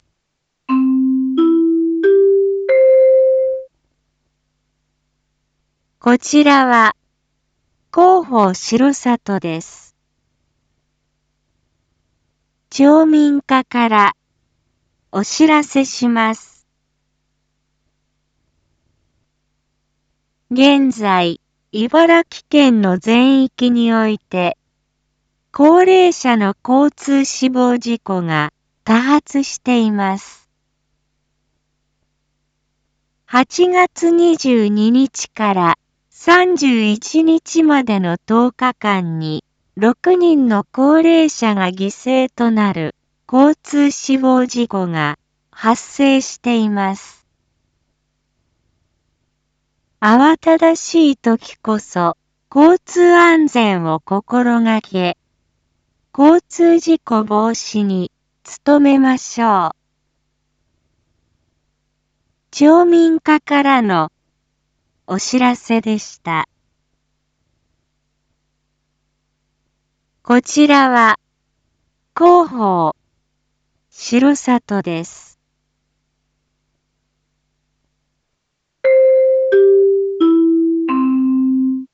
Back Home 一般放送情報 音声放送 再生 一般放送情報 登録日時：2023-09-01 19:01:23 タイトル：R5.9.1 19時放送分 インフォメーション：こちらは、広報しろさとです。